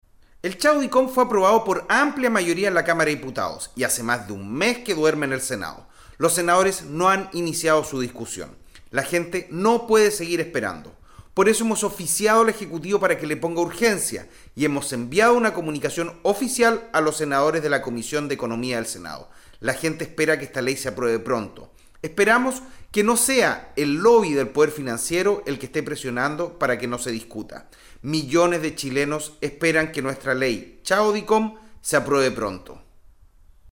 AUDIO : El diputado del PS Daniel Manouchehri, autor del proyecto “Chao Dicom”